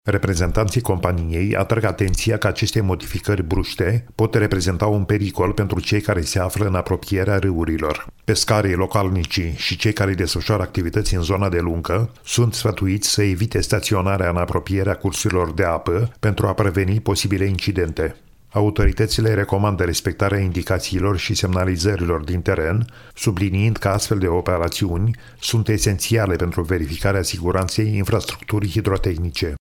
Corespondentul nostru